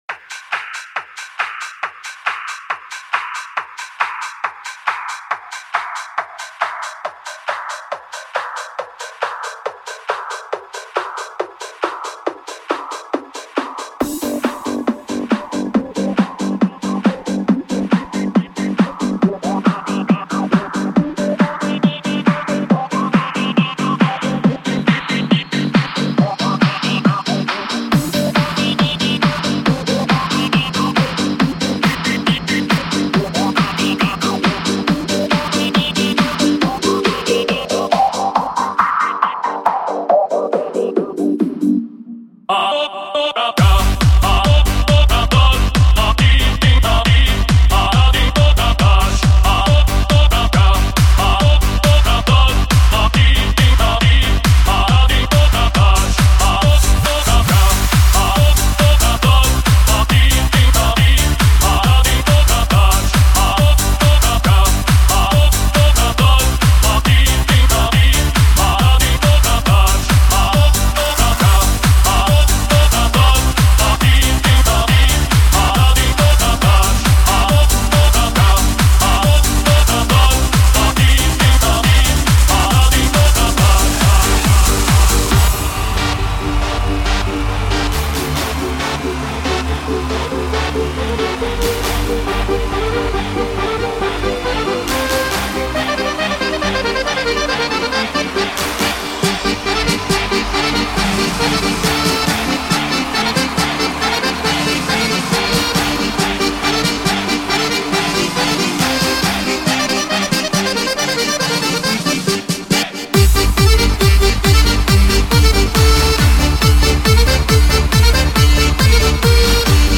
后面好像是一首俄语老歌。